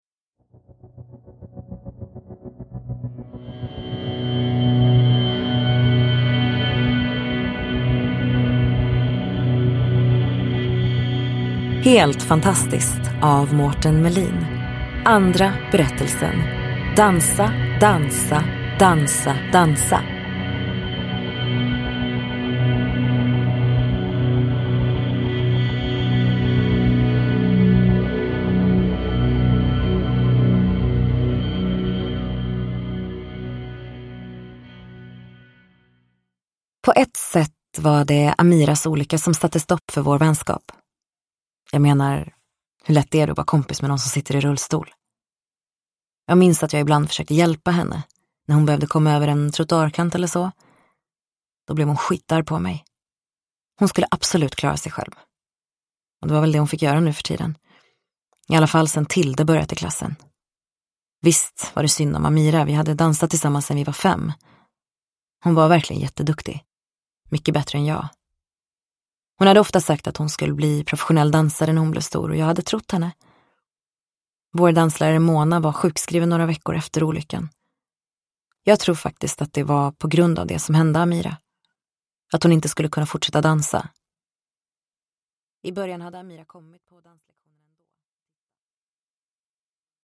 Dansa! Dansa! Dansa! Dansa! : en novell ur samlingen Helt fantastiskt – Ljudbok – Laddas ner